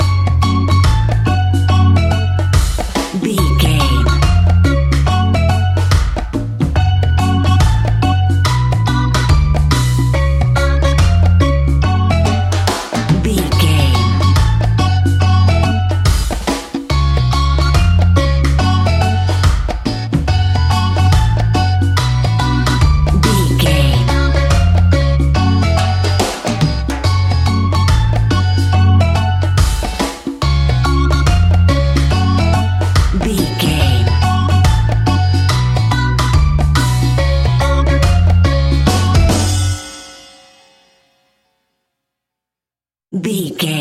Aeolian/Minor
F#
steelpan
drums
percussion
bass
brass
guitar